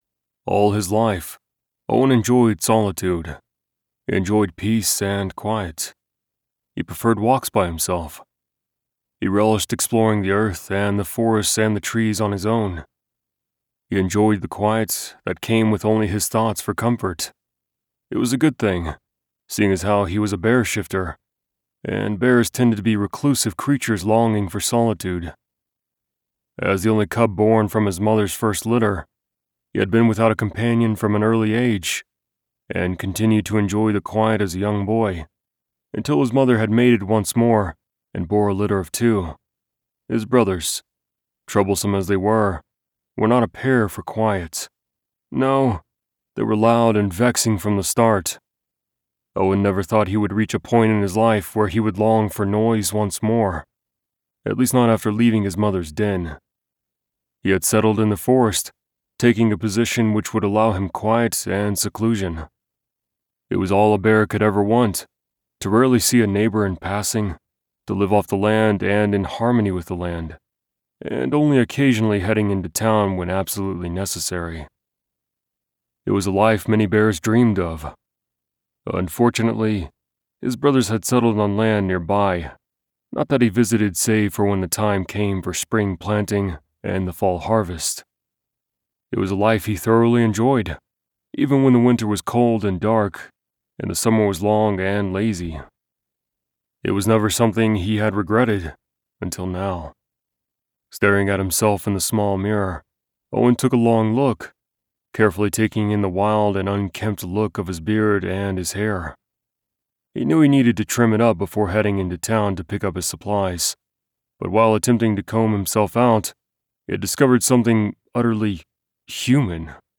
Owen is now available in Audiobook format, for all your listening desires!
000_Owen_Retail-Sample.mp3